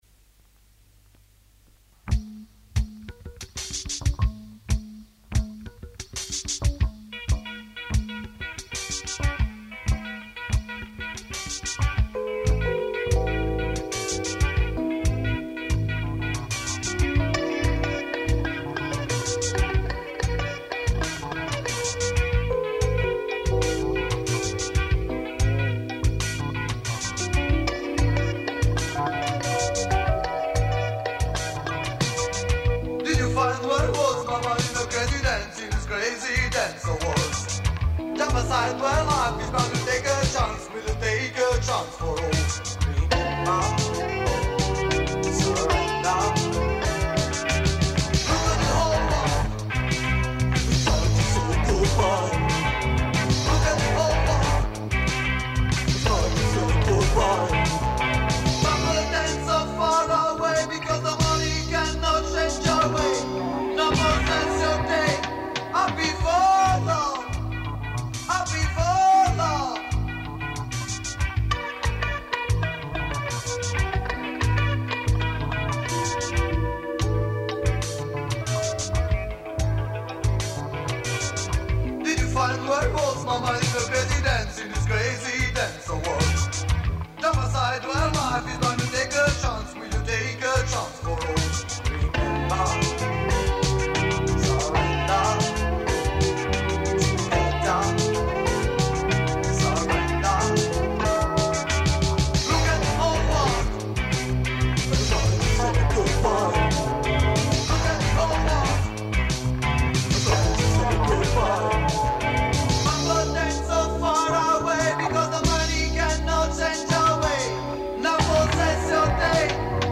La potente e calda voce
chitarra